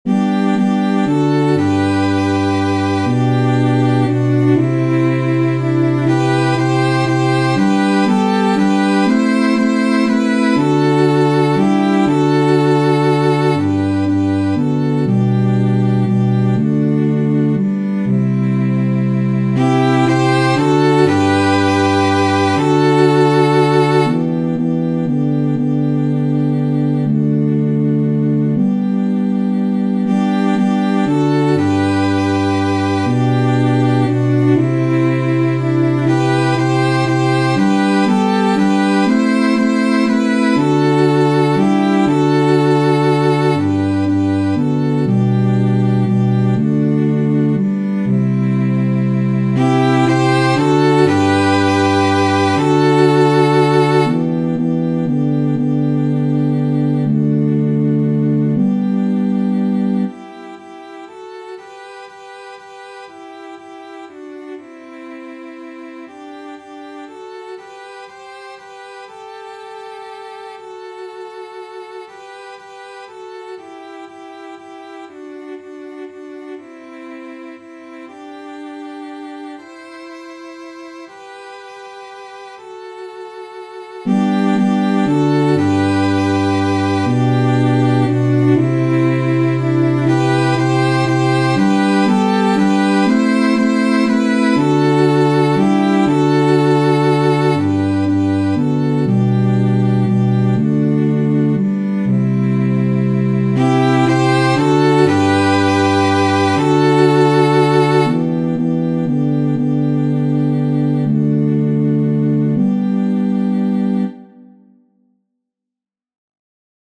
Antienne d'ouverture Téléchargé par